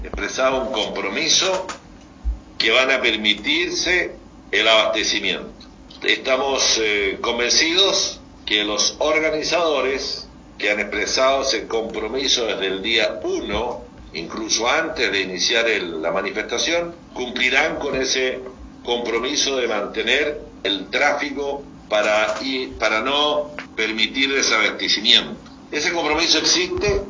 El Intendente de la región de Los Lagos a través de videoconferencia, se refirió a la movilización de los camioneros que se desarrolla actualmente a lo largo del país. El jefe regional, aseguró que los organizadores establecieron un compromiso de mantener el tráfico normal para no desabastecer al país.